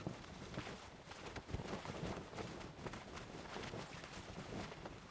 cloth_sail15.R.wav